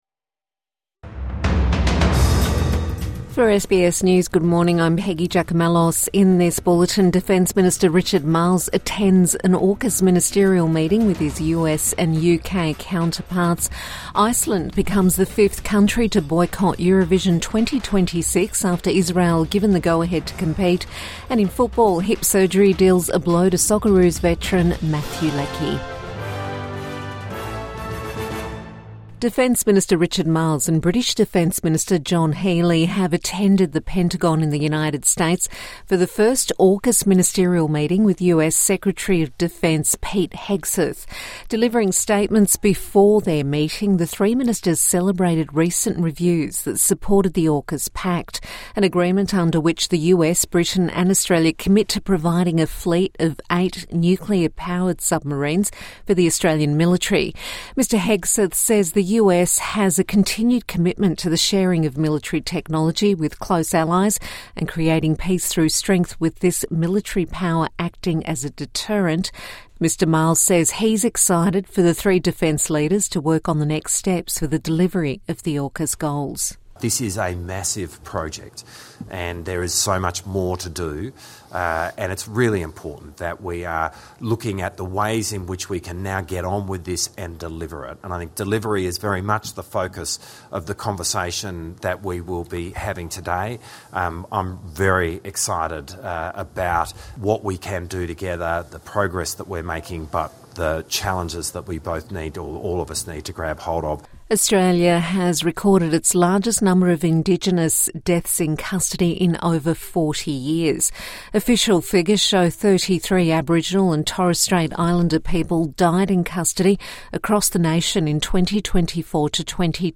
Richard Marles attends first AUKUS ministerial meeting |Morning News Bulletin 11 December 2025